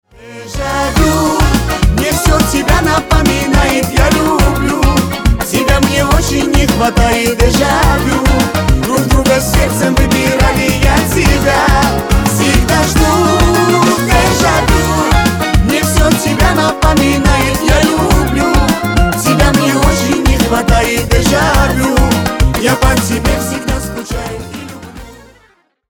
Шансон # Танцевальные